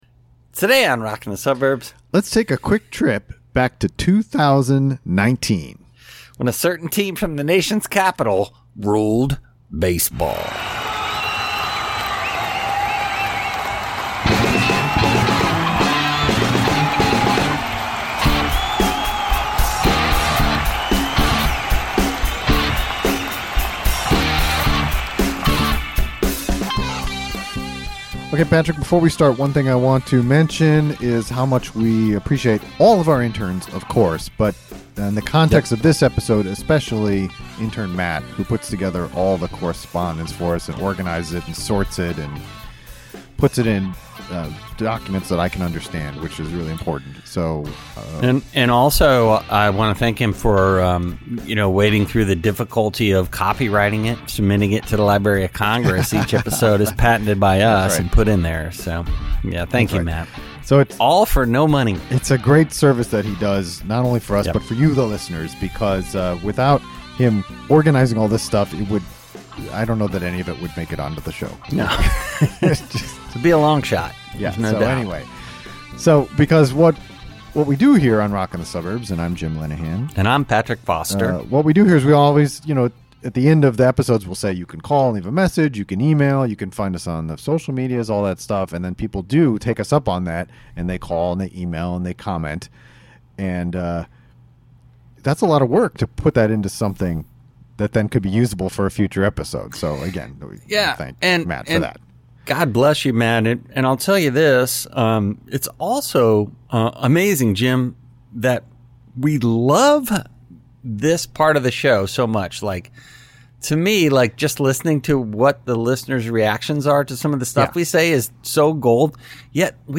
It's a listener new music roundup! We feature calls and emails about artists and tunes discovered over the past several months.